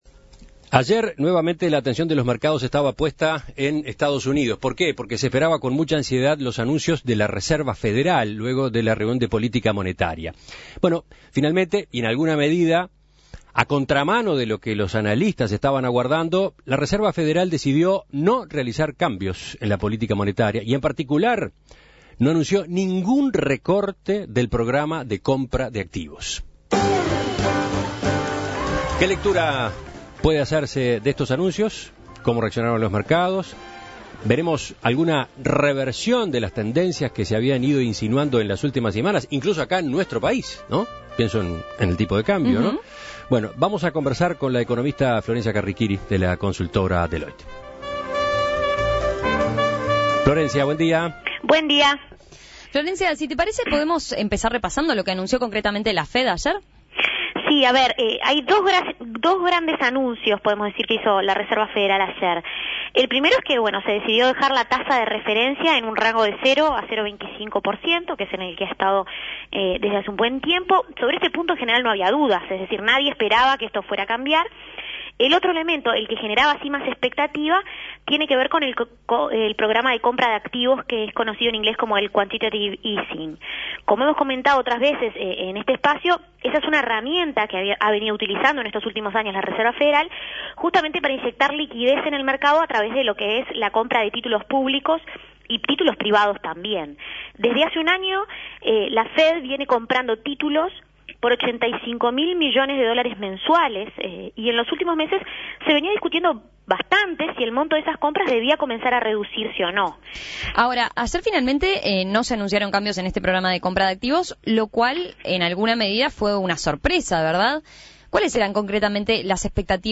Análisis Económico La Fed no hizo cambios en la política monetaria: ¿se verá una pausa en la salida de capitales de las economías emergentes?